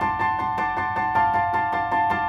Index of /musicradar/gangster-sting-samples/105bpm Loops
GS_Piano_105-D1.wav